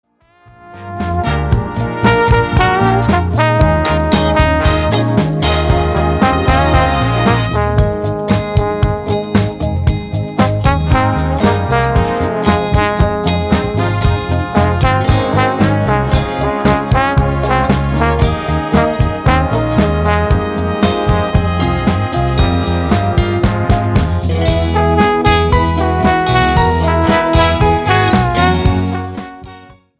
POP  (03.19)